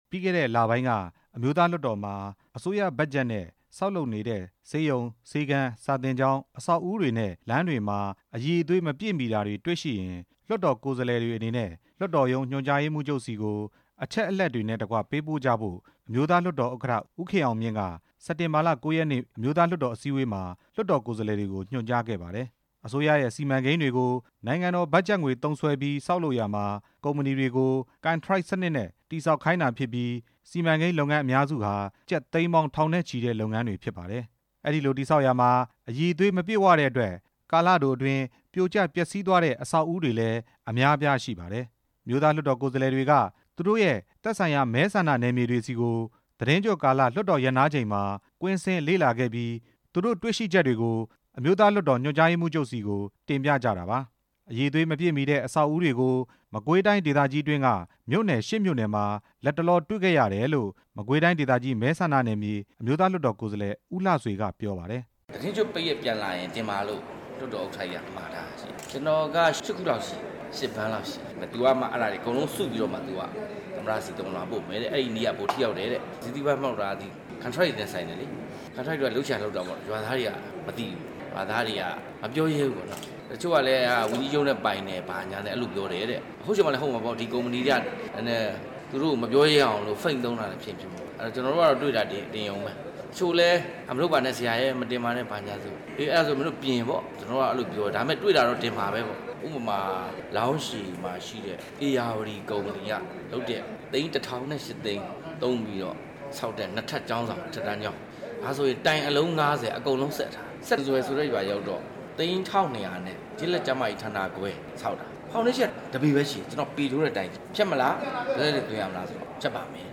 မကွေးတိုင်းဒေသကြီးအတွင်းက မြို့နယ် ၈ ခုမှာ မိမိတွေ့ရှိခဲ့တာတွေကို လွှတ်တော်မှာ တင်ပြမယ်လို့ မကွေးတိုင်းဒေသကြီး မဲဆန္ဒနယ်မြေရဲ့ အမျိုးသား လွှတ်တော် ကိုယ်စားလှယ် ဦးလှဆွေက RFA ကိုပြောပါတယ်။